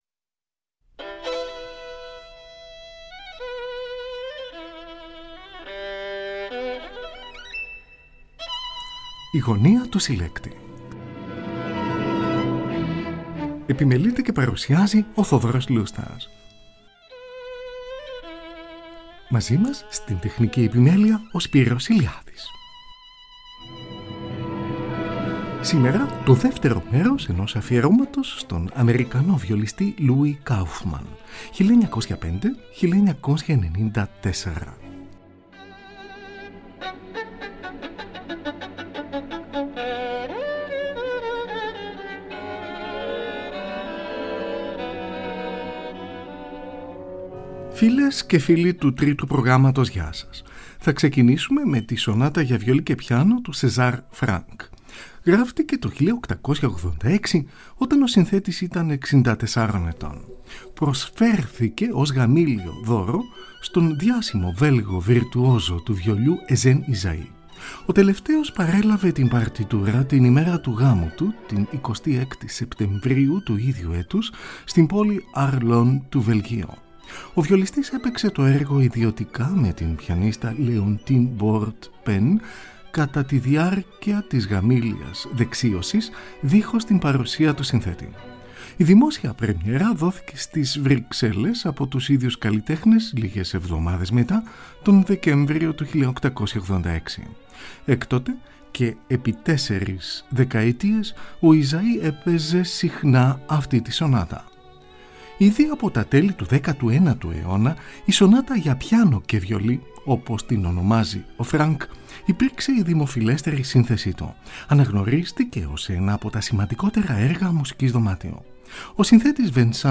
σονάτα για βιολί και πιάνο.